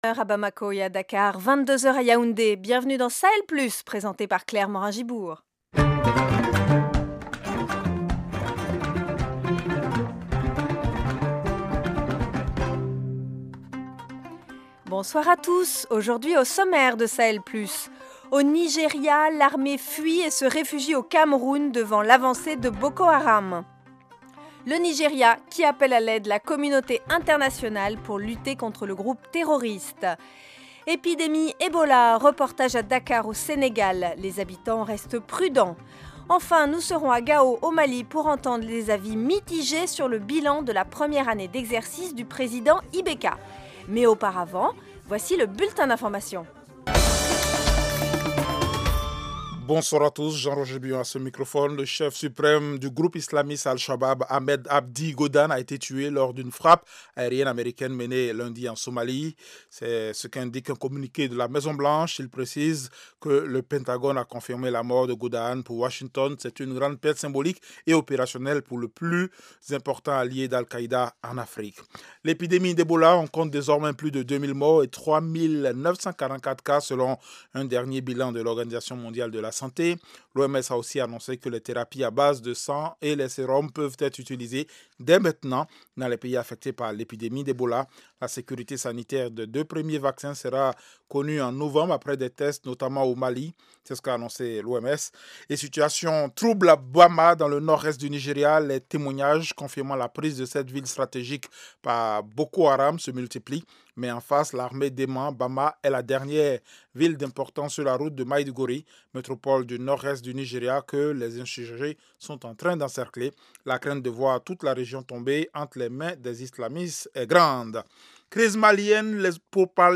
Au programme : le Nigéria appelle à l’aide la communauté internationale pour lutter contre Boko Haram alors que sa propre armée fuit au Cameroun. Ebola : reportage à Dakar au Sénégal où les habitants restent prudents. A Gao au Mali, avis mitigés sur le bilan de la 1ère année du gouvernement IBK.